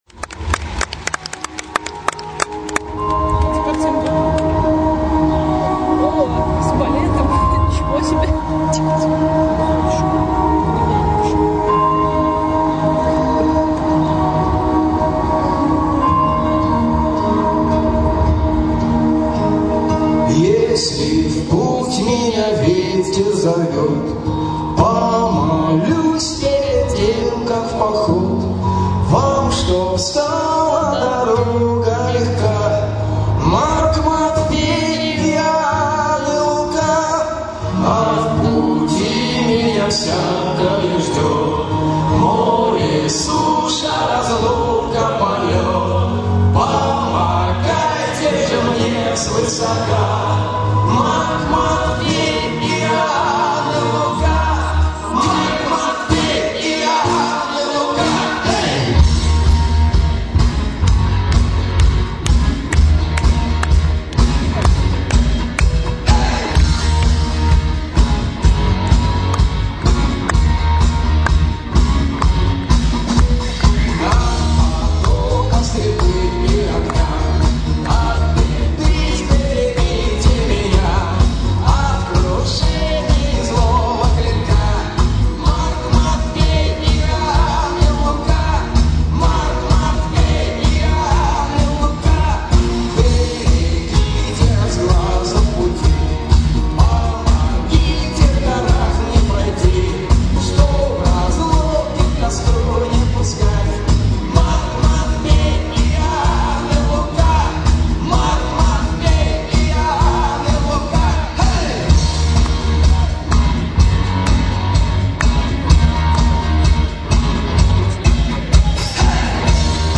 24 февраля 2008 года, Москва, Кремль.